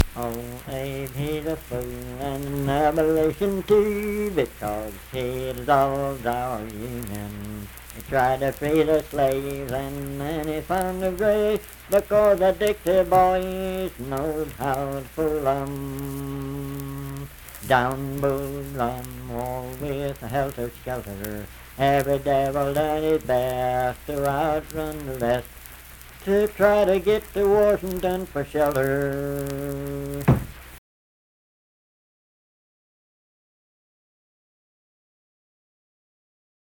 Unaccompanied vocal music
Performed in Dundon, Clay County, WV.
Political, National, and Historical Songs, War and Soldiers
Voice (sung)